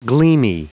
Prononciation du mot gleamy en anglais (fichier audio)
Prononciation du mot : gleamy